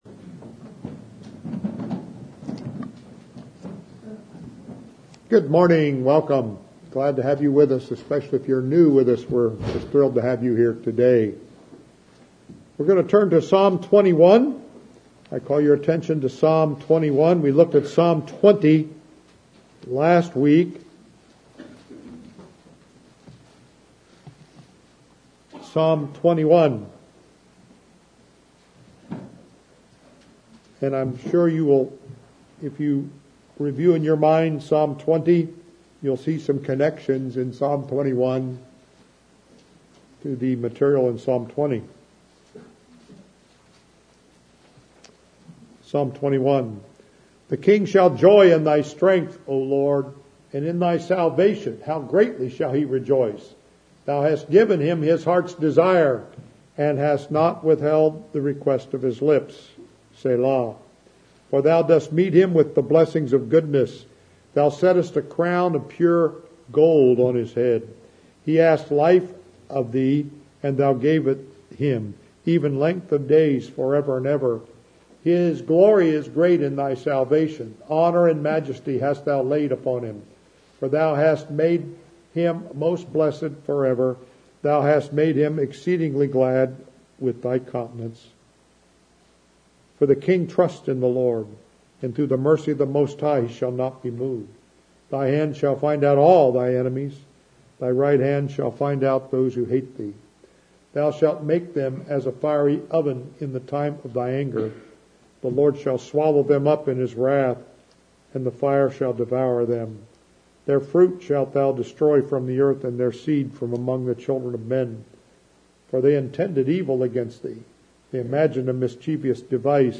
Psalm 21 Audio Sermon